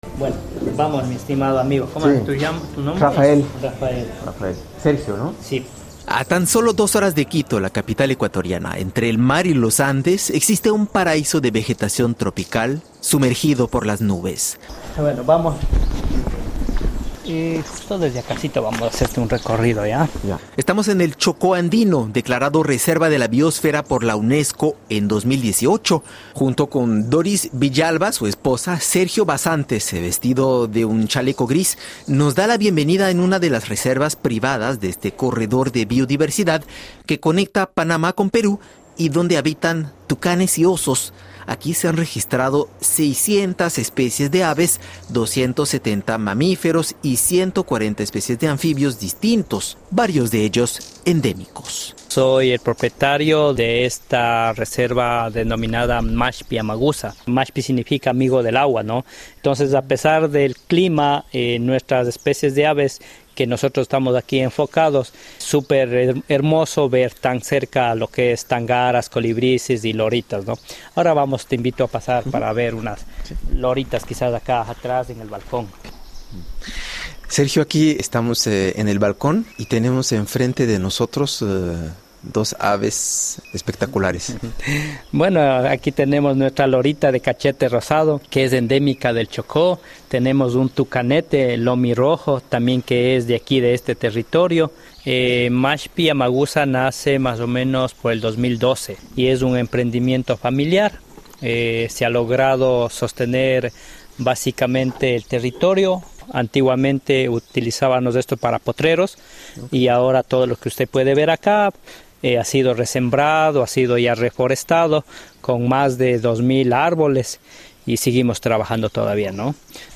En la región ecuatoriana del Chocó Andino, un oasis de biodiversidad, a dos horas de Quito, las comunidades campesinas aprobaron en 2023 prohibir las nuevas concesiones mineras. Sin embargo, la actividad minera sigue presente. Reportaje.